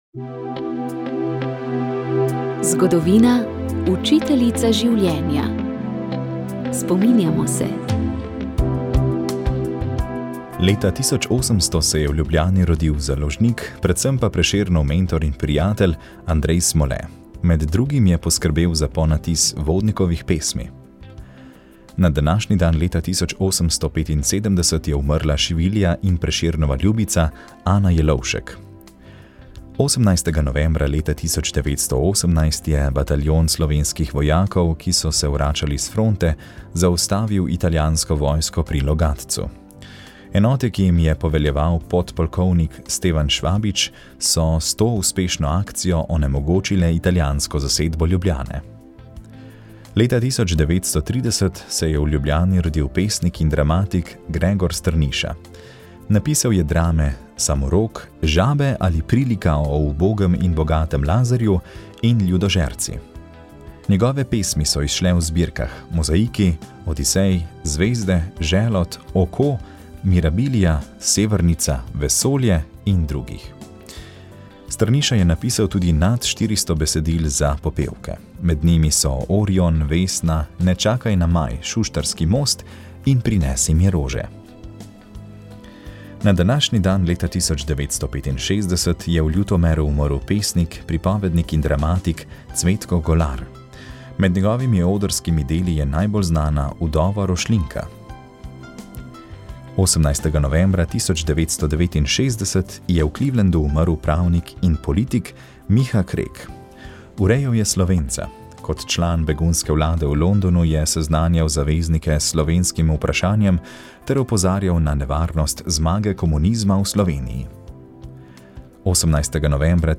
pričevanje